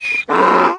Звук с криком мула